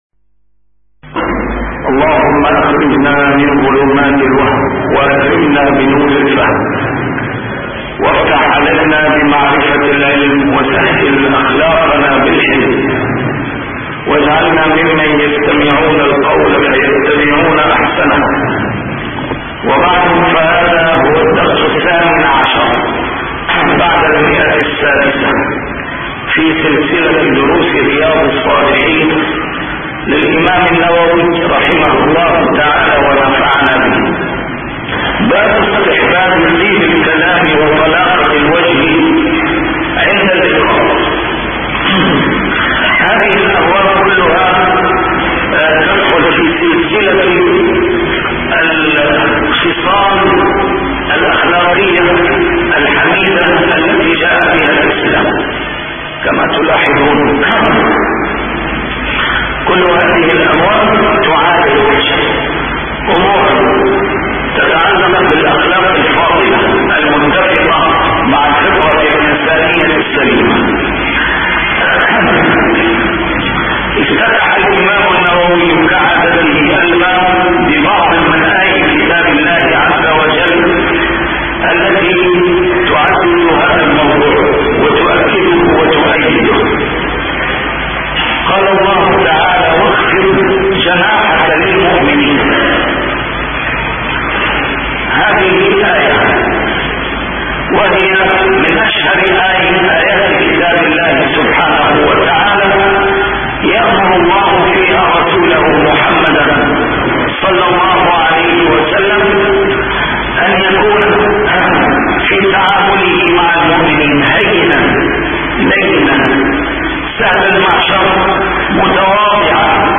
A MARTYR SCHOLAR: IMAM MUHAMMAD SAEED RAMADAN AL-BOUTI - الدروس العلمية - شرح كتاب رياض الصالحين - 618- شرح رياض الصالحين: طيب الكلام عند اللقاء